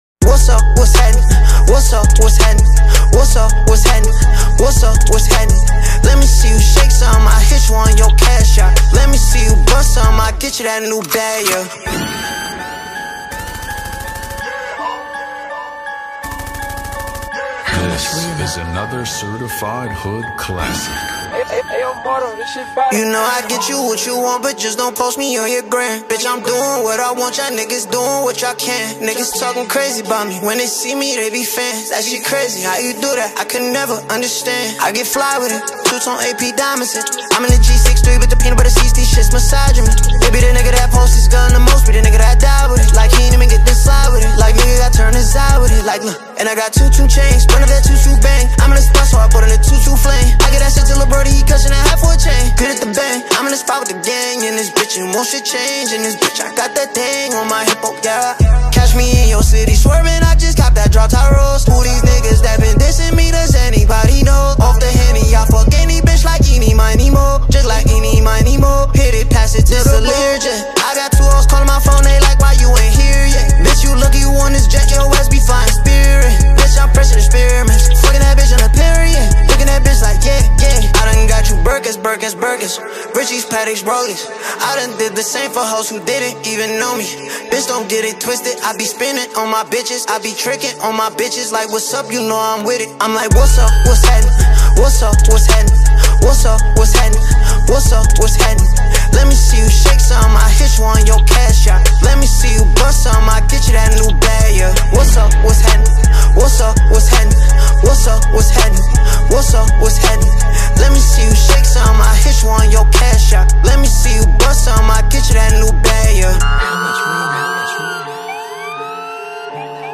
ژانر: رپ